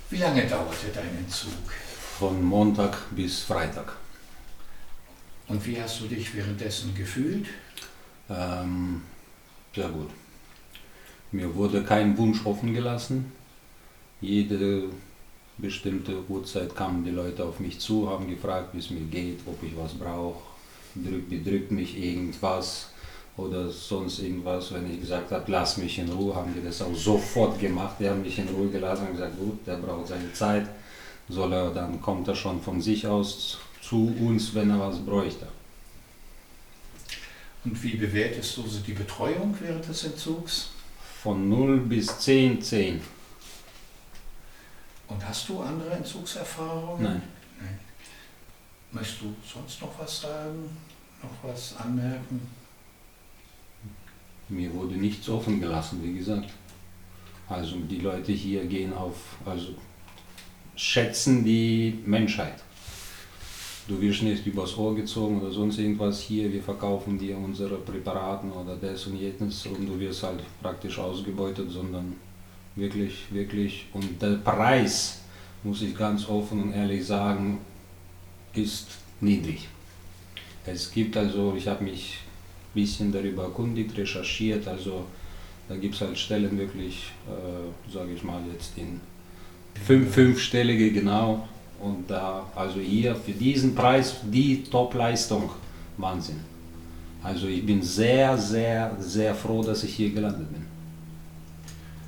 Das sagen Patienten aus Deutschland über das ESCAPE-Verfahren